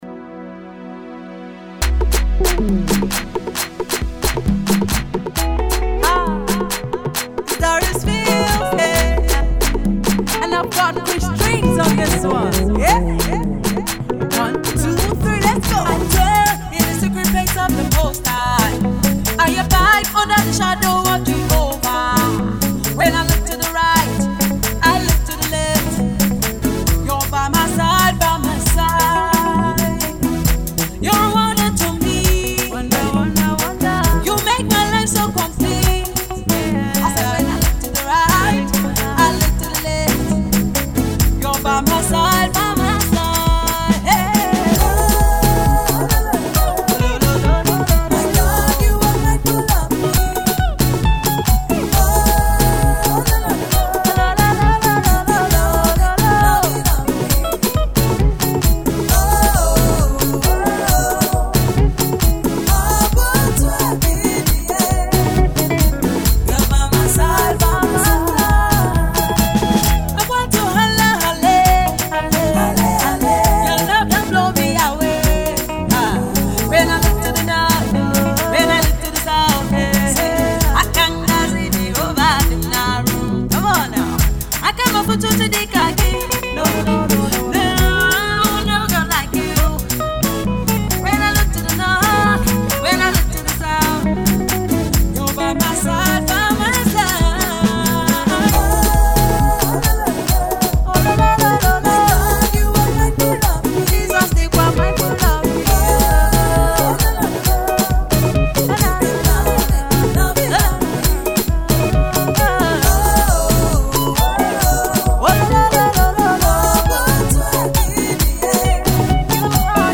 an uptempo, upbeat and joyful dance song